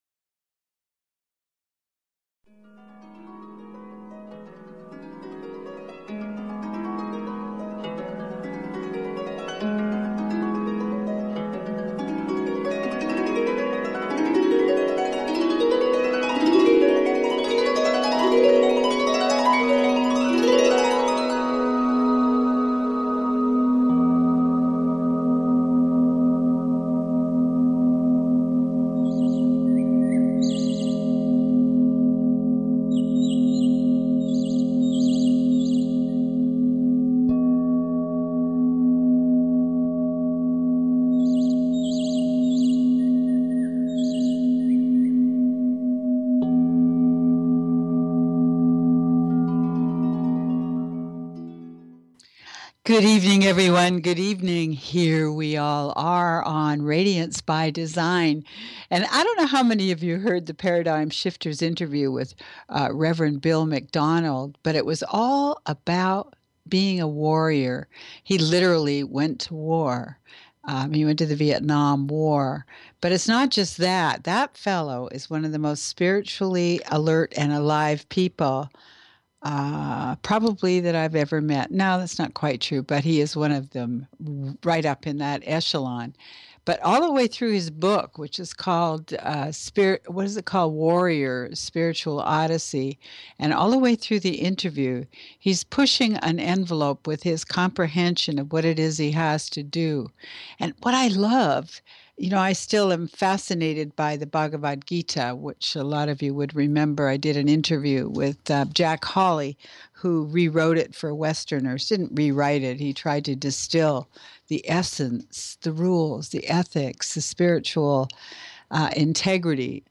Live Spiritual Readings